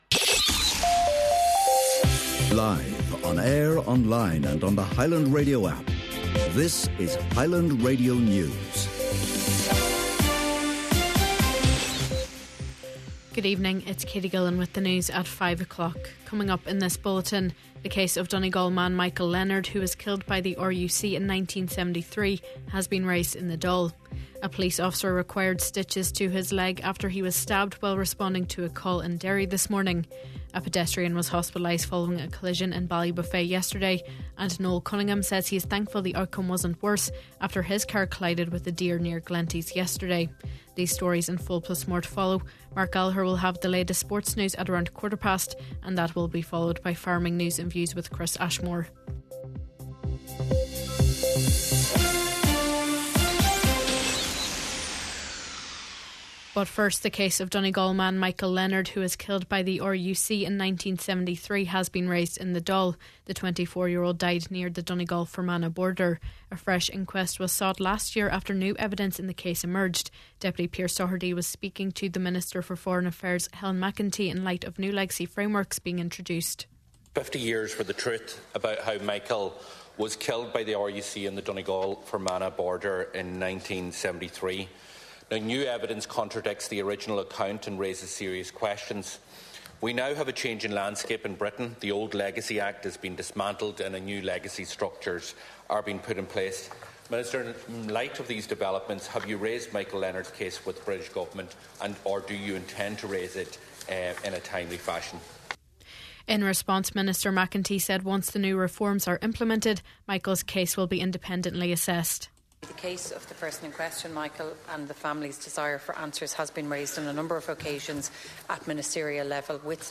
Main Evening News, Sport, Farming News and Obituary Notices – Thursday November 27th